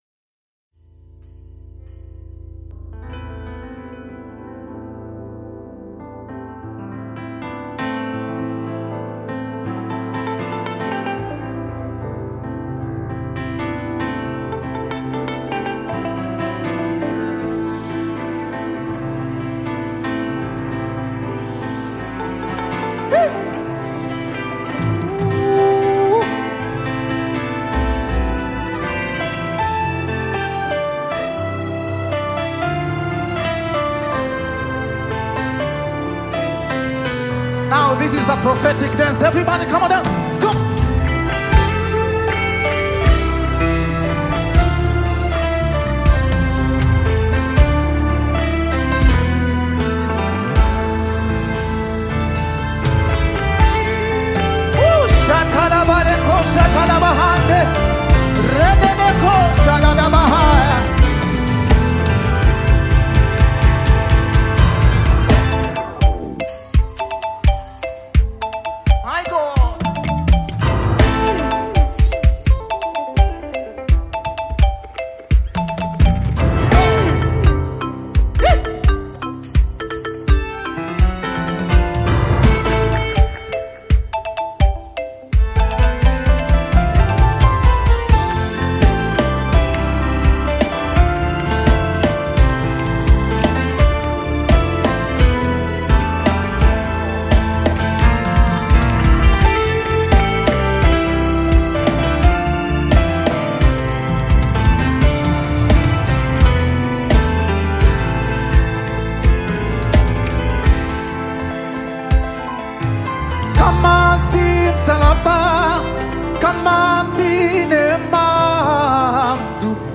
Nyimbo za Dini music
Gospel music track
Tanzanian Gospel artist, singer and songwriter